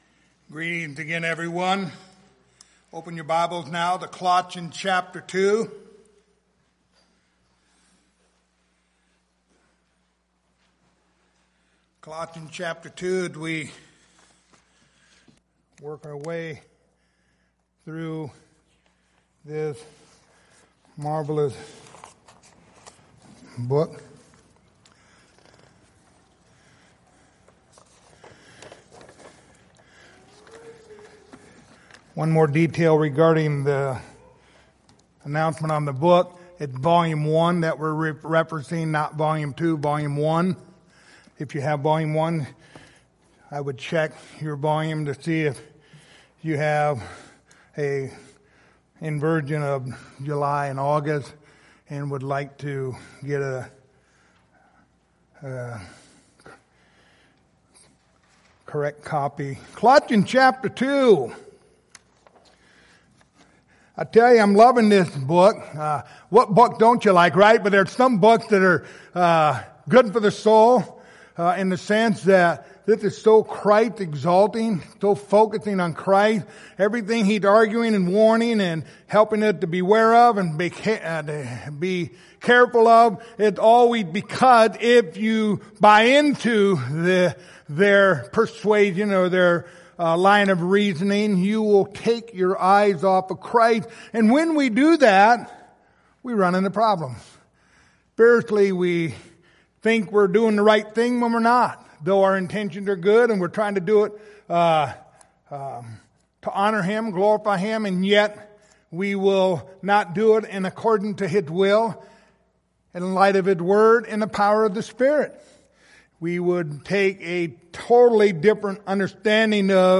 Series: The Book of Colossians Passage: Colossians 2:18-19 Service Type: Sunday Morning